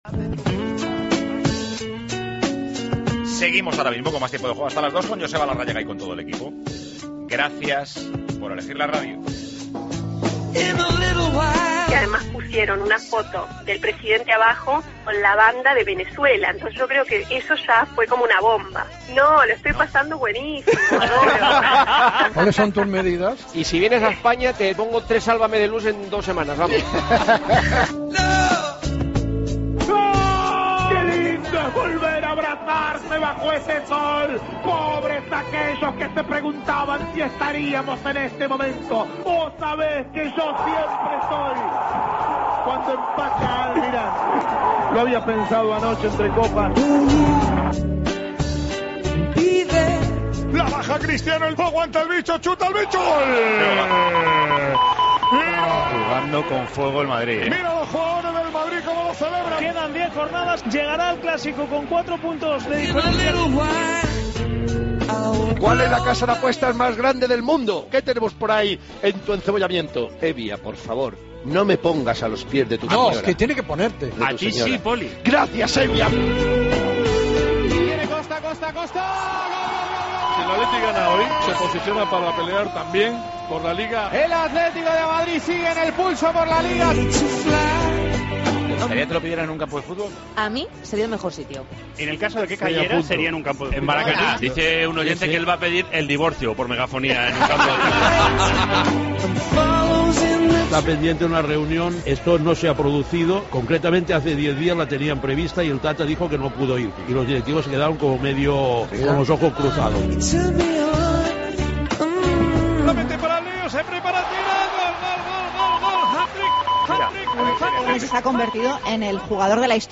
Nos visita Jaime Urrutia y nos canta "Pantalón vaquero", hablamos de las peticiones de bodas, Messi marca un hat trick y mucho más.
Con Paco González, Manolo Lama y Juanma Castaño